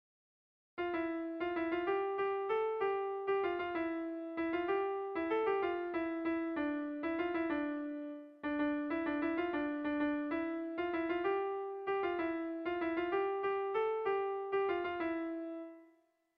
Gabonetakoa
Erritmo interesgarria du.
Zortziko txikia (hg) / Lau puntuko txikia (ip)